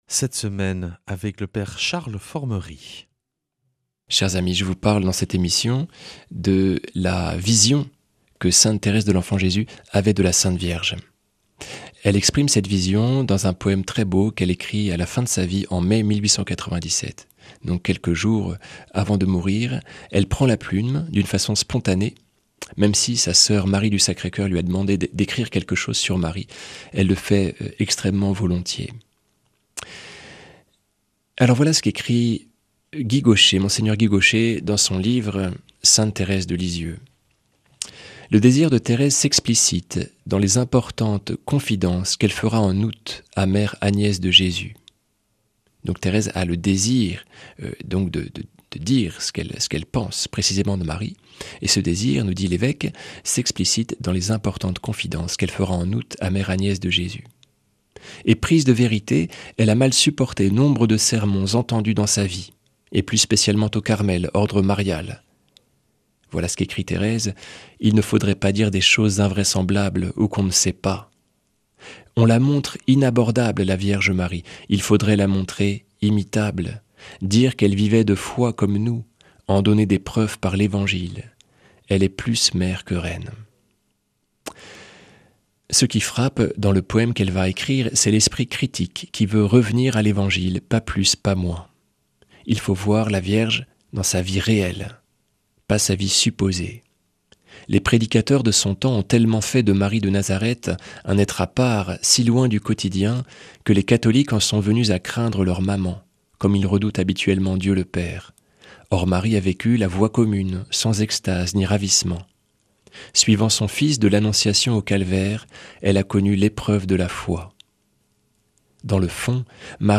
jeudi 2 octobre 2025 Enseignement Marial Durée 10 min